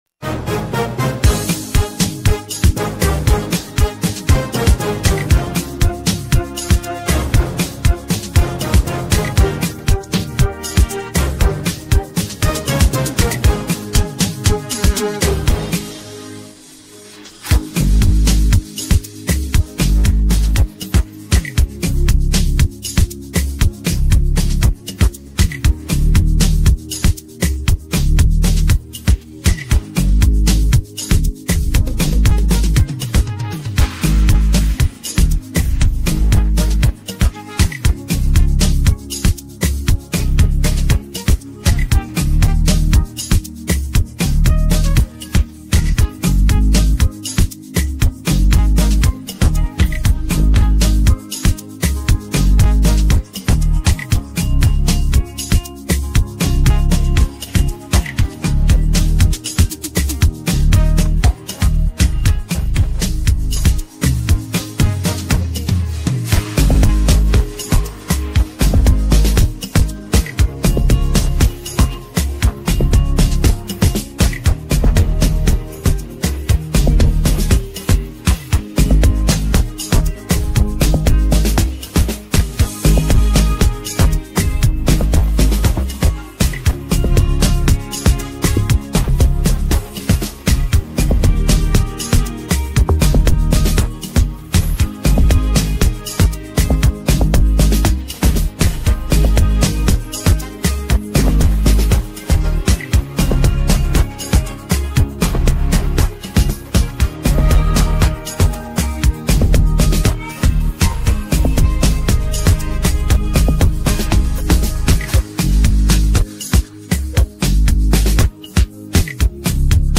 timeless Afrobeat rhythm